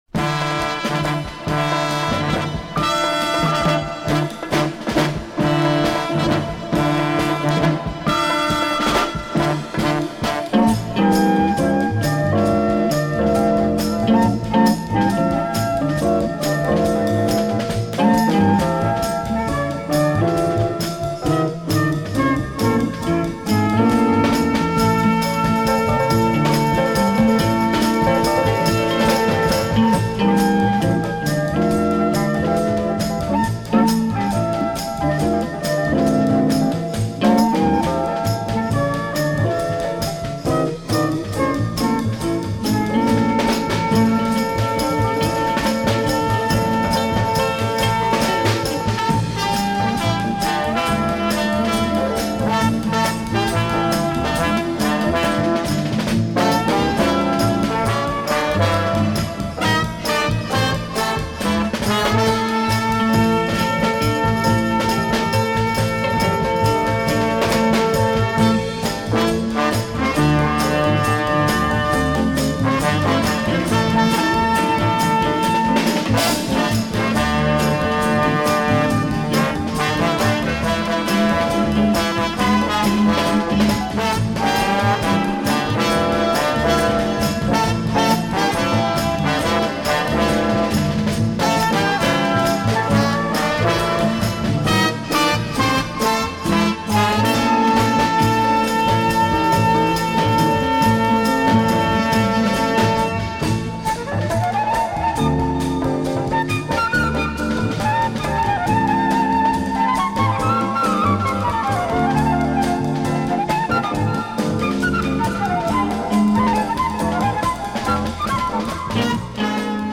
A beautiful soundtrack for the cult film